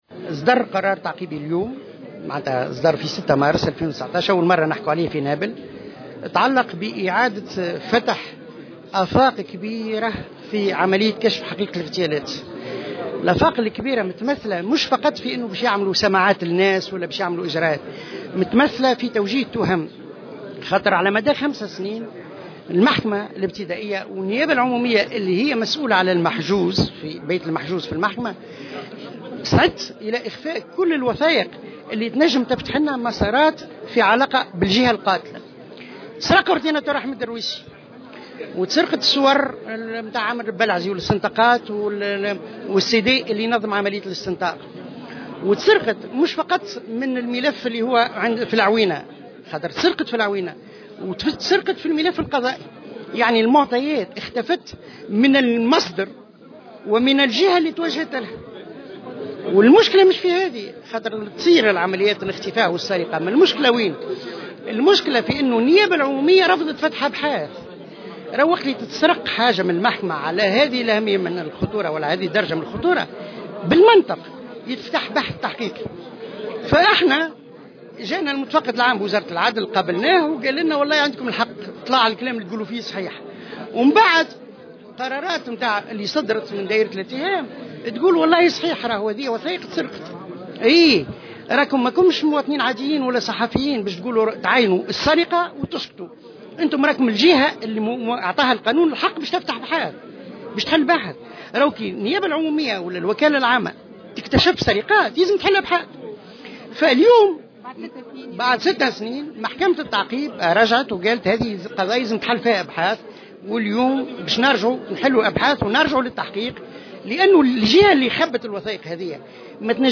على هامش ندوة صحفية عقدتها الهيئة، اليوم السبت، في الحمامات